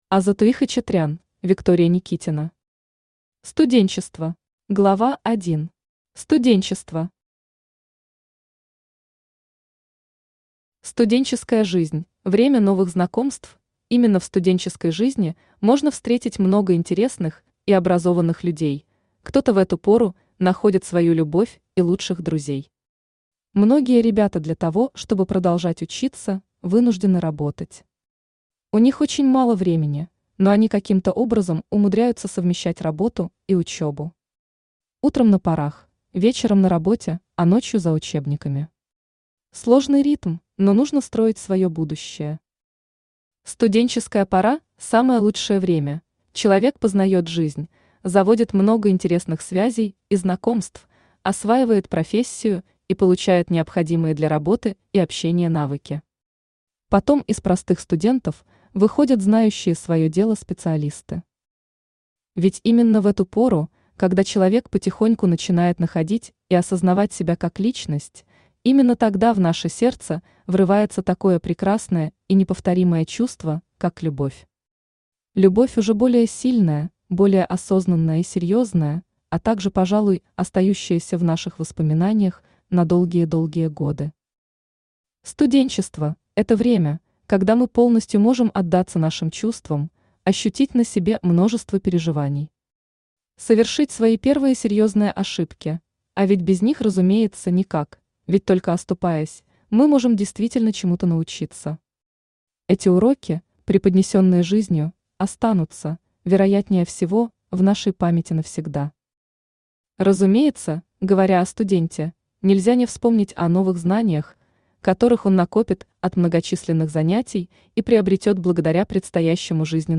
Аудиокнига Студенчество | Библиотека аудиокниг
Aудиокнига Студенчество Автор Азатуи Араовна Хачатрян Читает аудиокнигу Авточтец ЛитРес.